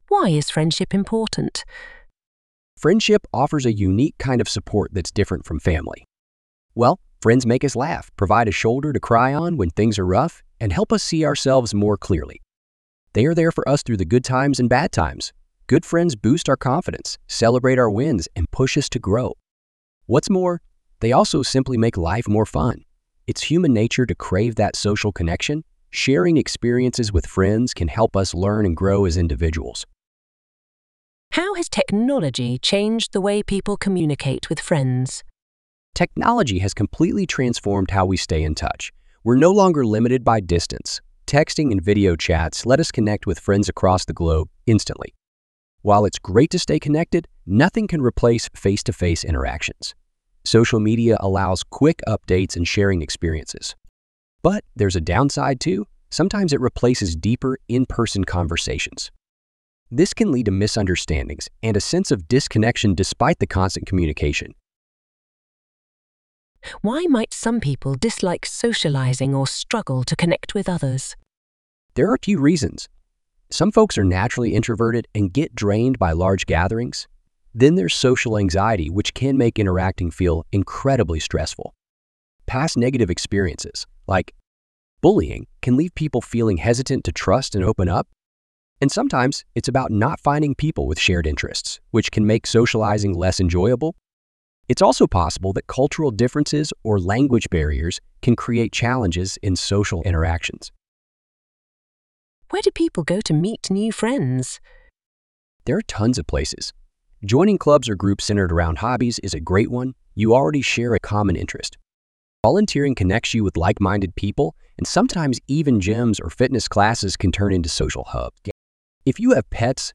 Trong bài viết này, Mc IELTS chia sẻ câu trả lời mẫu band 8.0+ từ cựu giám khảo IELTS, kèm theo các câu hỏi mở rộng và bản audio từ giáo viên bản xứ để bạn luyện phát âm, ngữ điệu và tốc độ nói tự nhiên.